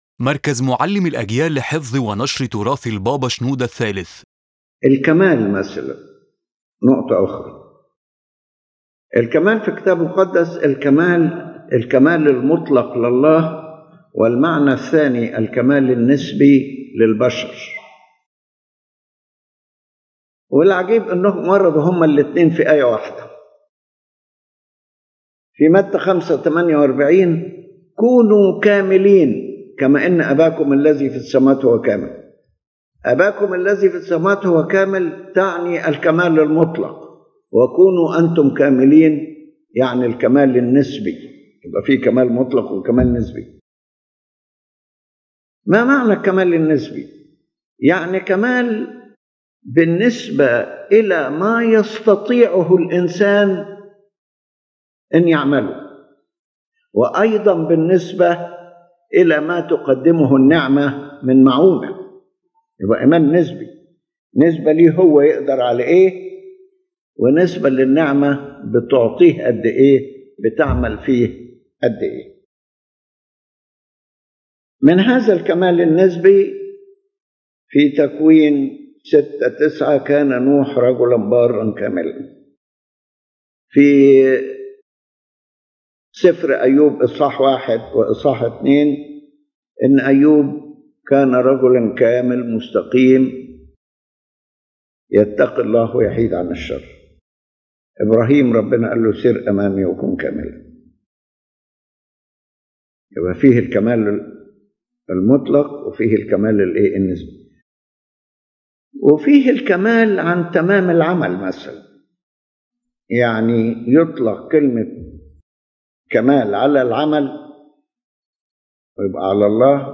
His Holiness Pope Shenouda III explains that the word perfection in the Holy Bible has more than one aspect and must be distinguished according to the occasion and the text.